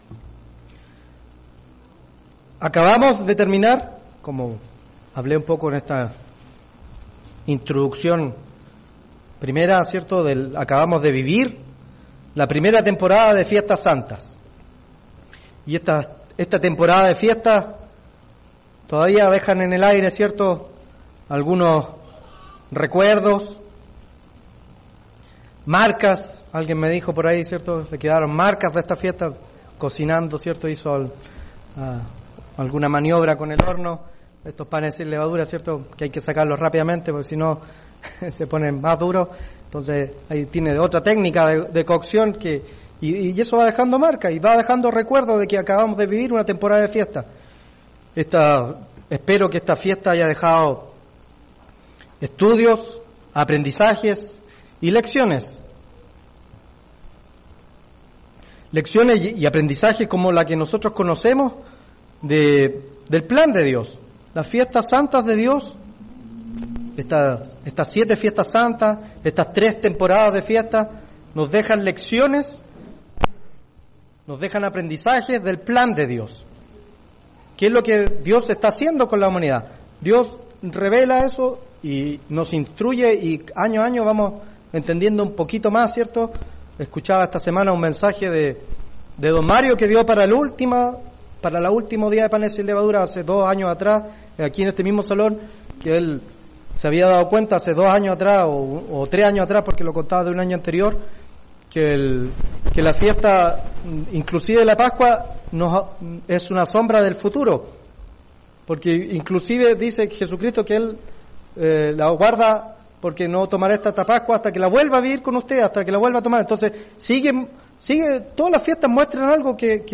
Ser parte de la familia de Dios requiere que pongamos todos nuestros talentos y atención en aquello que Dios considera importante, incluso cuando no podamos comprender cabalmente cuál es la voluntad del Eterno. Mensaje entregado el 14 de abril de 2018.